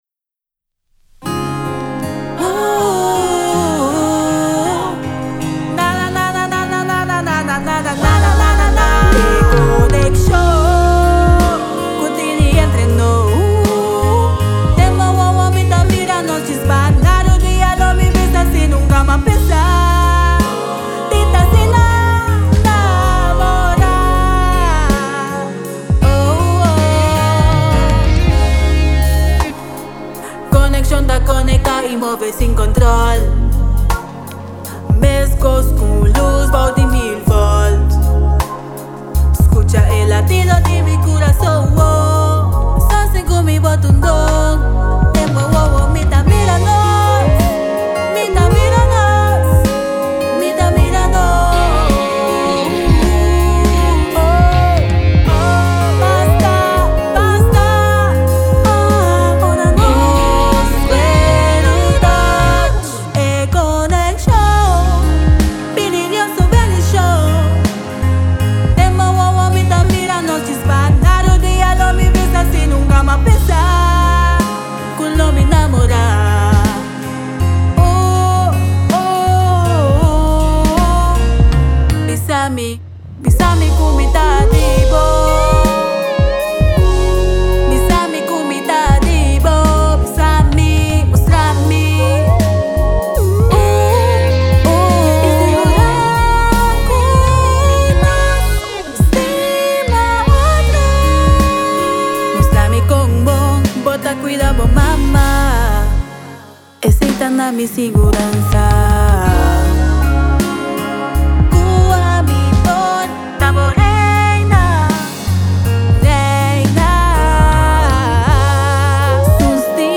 a skohe un estilo soul old school